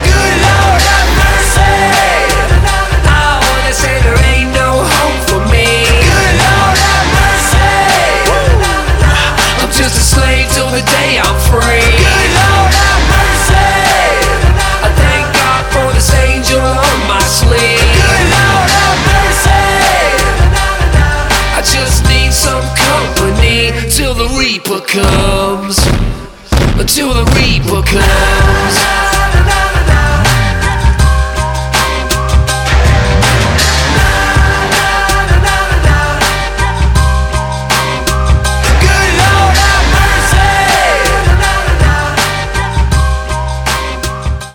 громкие
Rap-rock
Trap
Прикольный реп со словами "на-на-нанана"